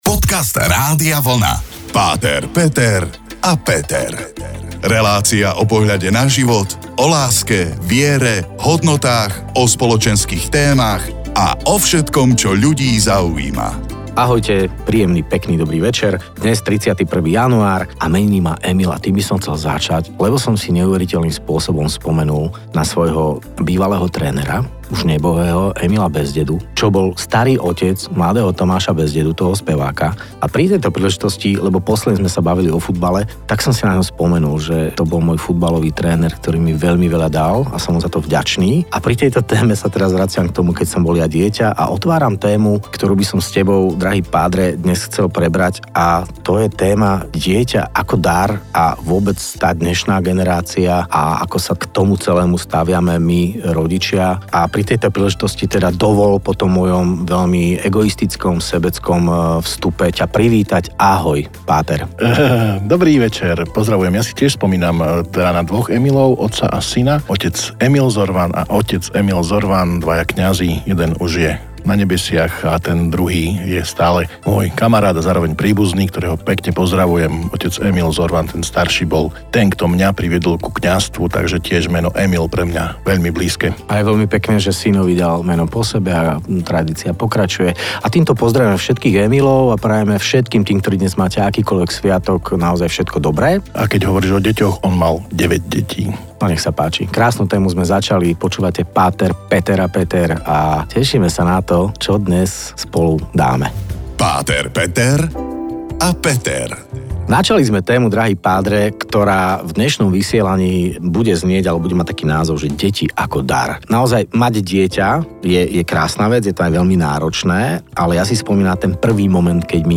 To najlepšie z vysielania rádia Vlna.